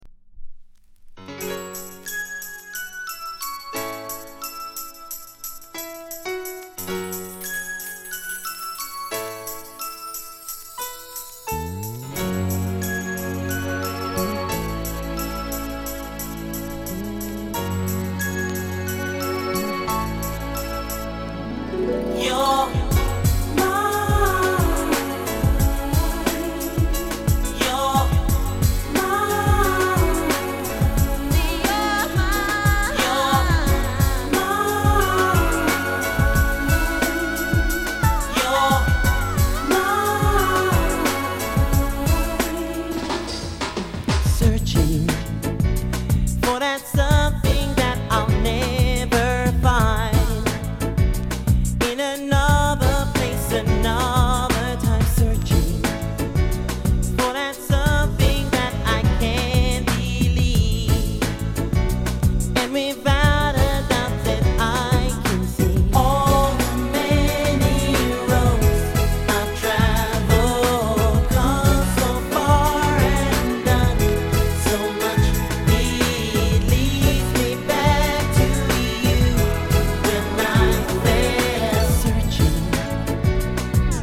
当前位置 > 首页 >音乐 >唱片 >世界音乐 >雷鬼